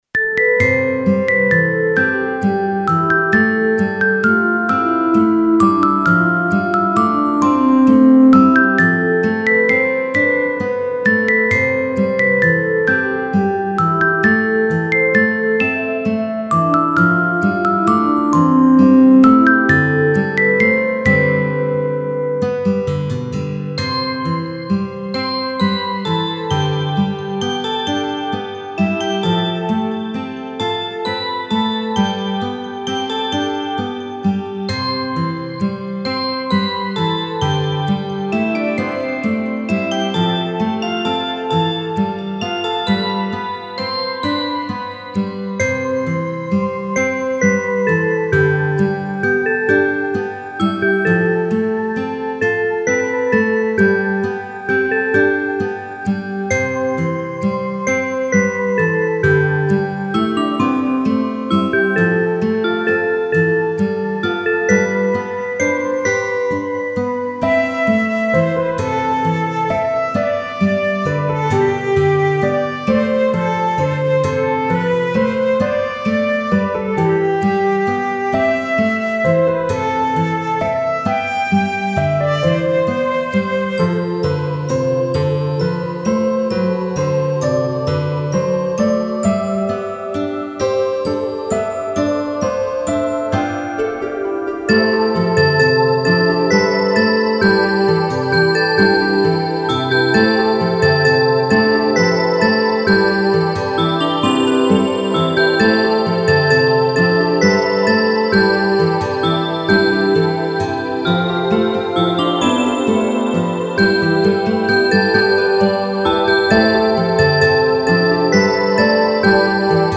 フリーBGM イベントシーン 優しい
フェードアウト版のmp3を、こちらのページにて無料で配布しています。